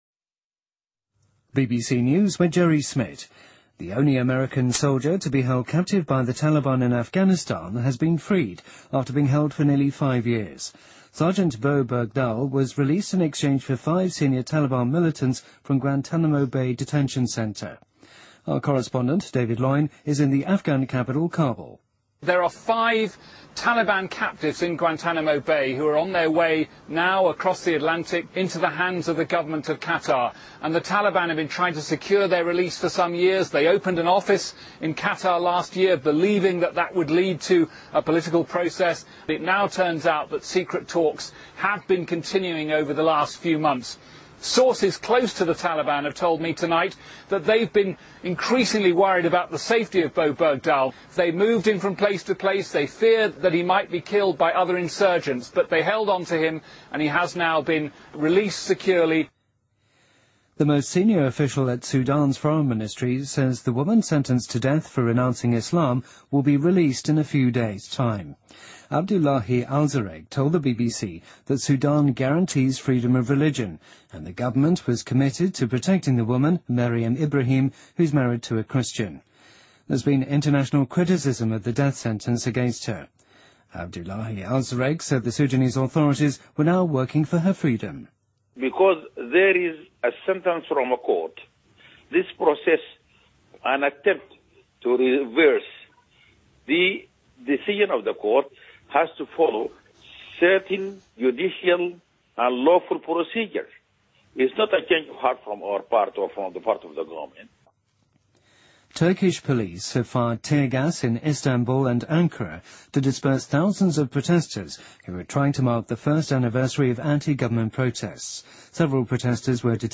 BBC news,美国用5名塔利班极端分子囚犯换回陆军中士贝里达尔